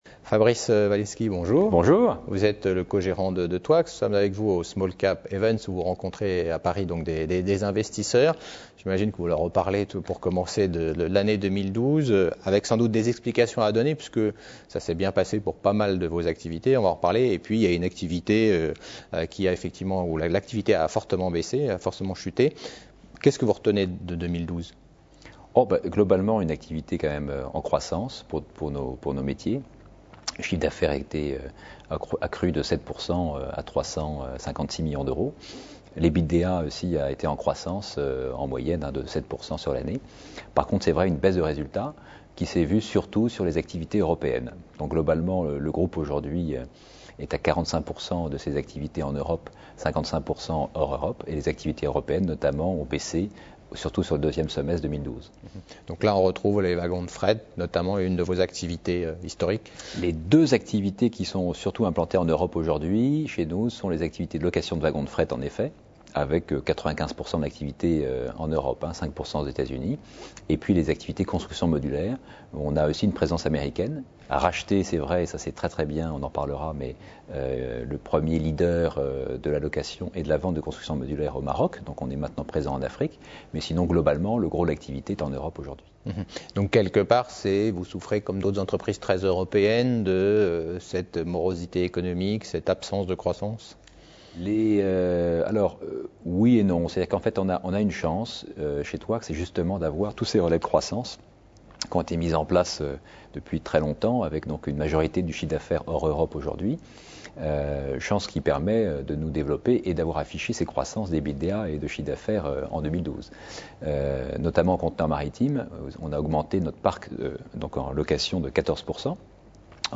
Smallcap Events 2013 : Interview